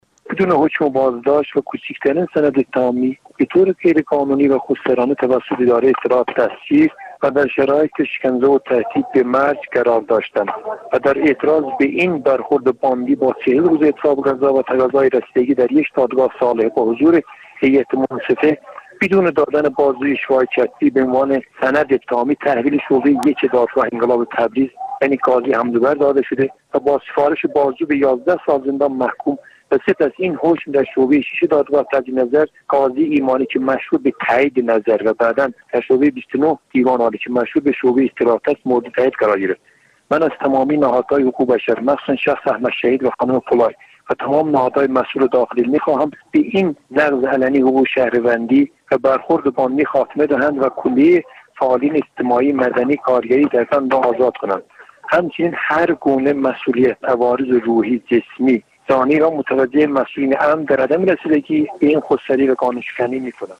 در زندان گوهردشت
پیامی صوتی